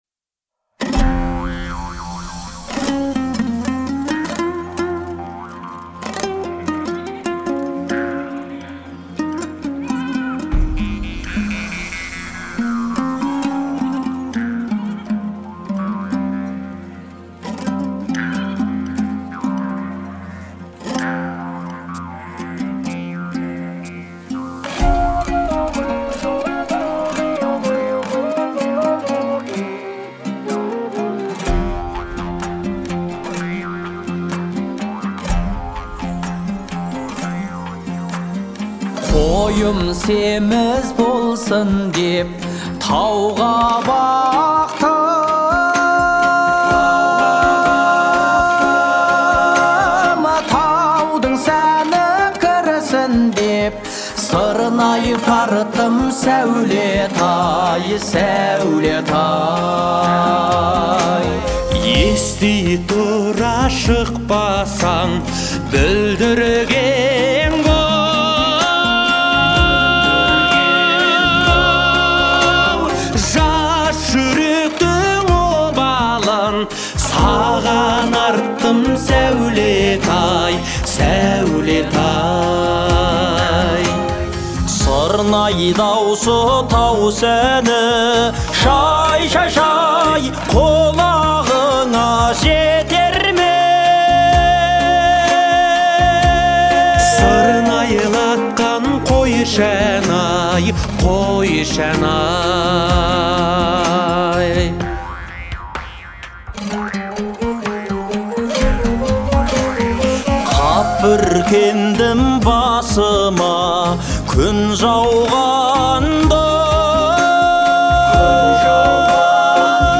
это яркий пример казахского фольклорного попа